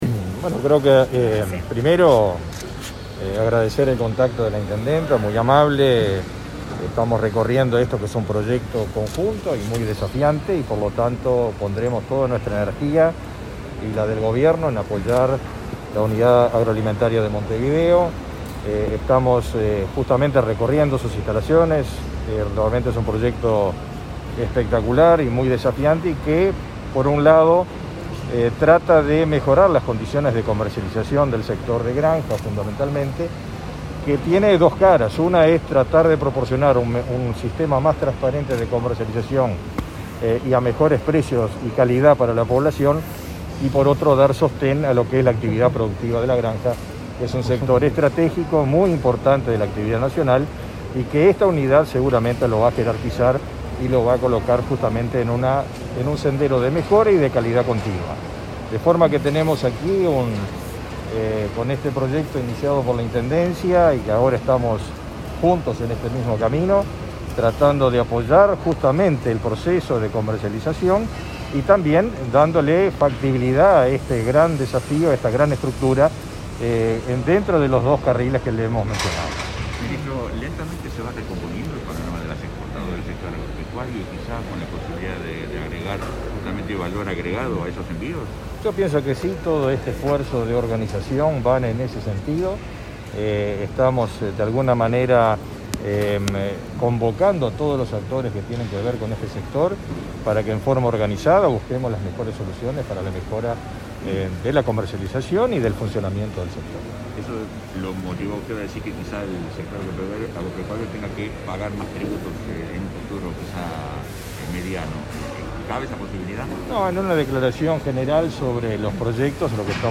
Declaraciones del ministro de Ganadería, Agricultura y Pesca, Fernando Mattos